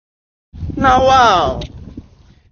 Nawa o (comedy sound effect)
Download sound fx titled ”Nawa o” comedy sound effect.
Nawa-o-comedy-sound-effect.mp3